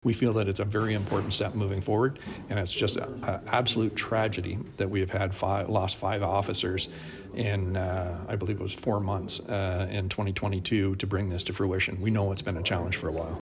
At a Belleville Police Services Board meeting Thursday Chief Mike Callaghan said too many repeat violent offenders are being released on bail and the time is now for reform of the bail system.
mike-callaghan-bail.mp3